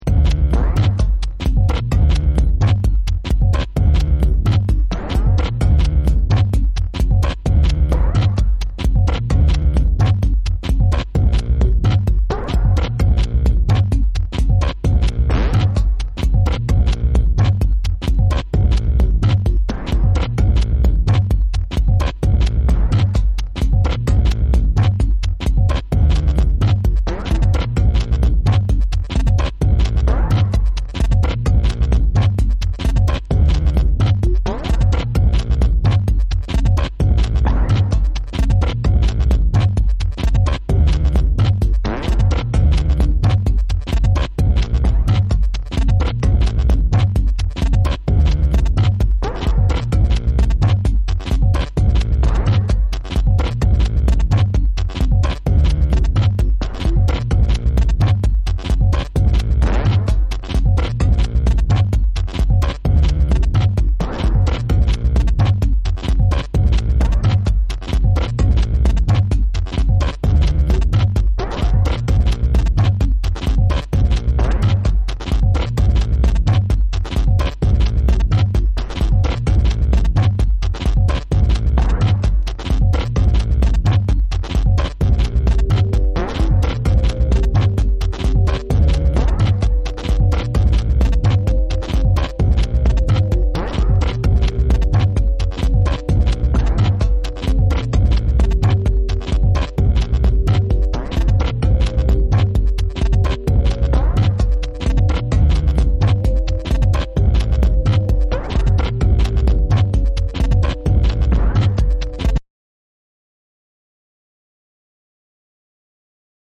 低空飛行でジワジワと展開し、ブレイクから完全に狂気の世界へ引きづり込まれるアシッド・ミニマル・ナンバー